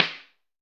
B.B SN 3.wav